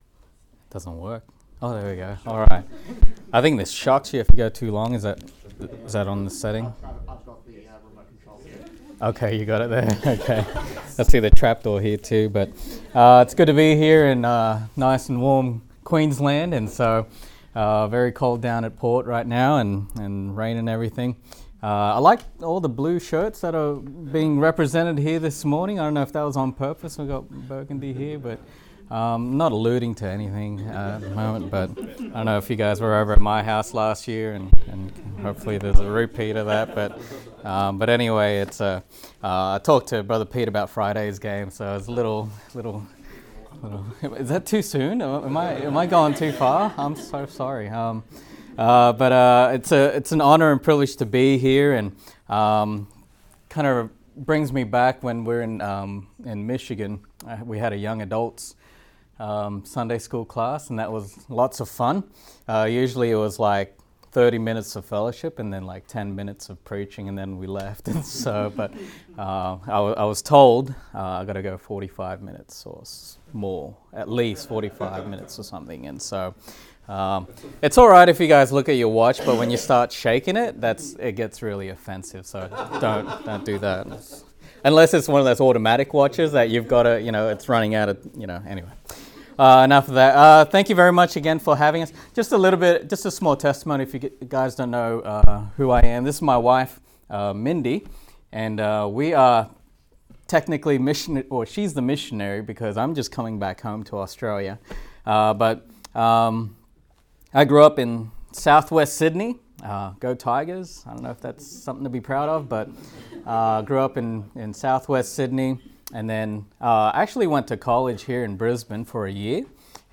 Sermons | Good Shepherd Baptist Church
Leadership Conference 2024